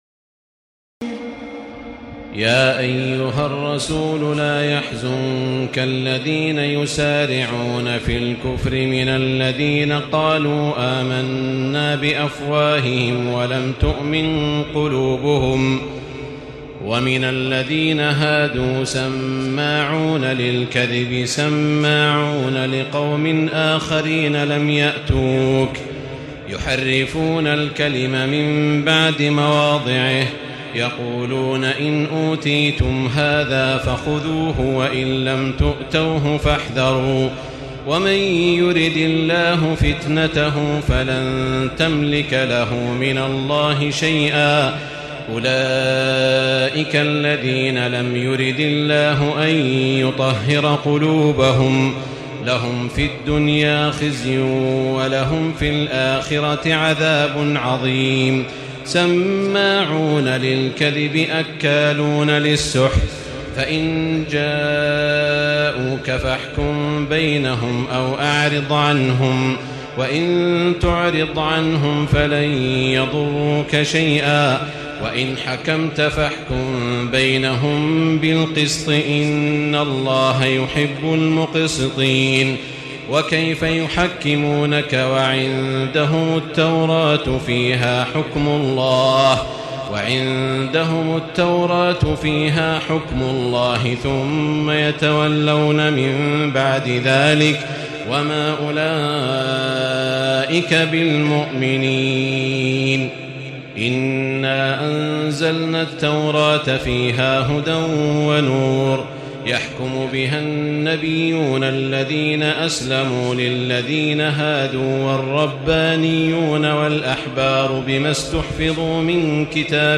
تراويح الليلة السادسة رمضان 1436هـ من سورة المائدة (41-104) Taraweeh 6 st night Ramadan 1436H from Surah AlMa'idah > تراويح الحرم المكي عام 1436 🕋 > التراويح - تلاوات الحرمين